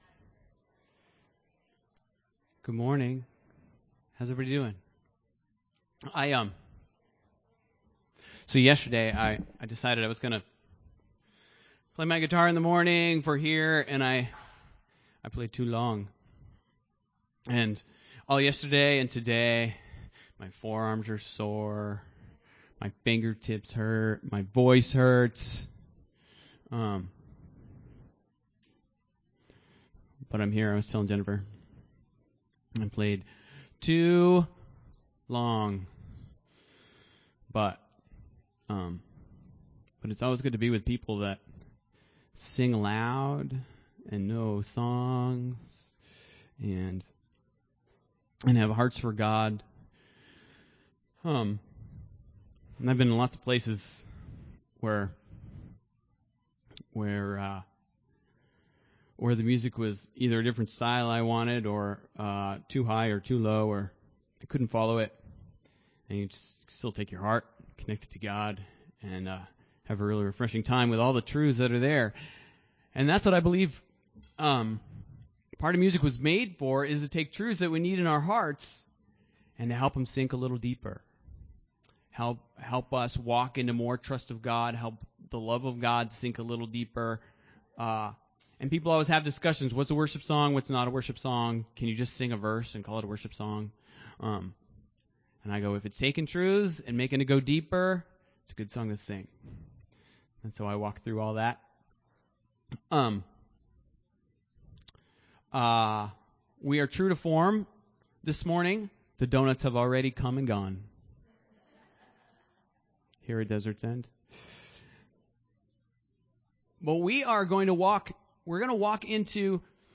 Several members of our church shared what they learned from our series on James and how they are applying it to their lives.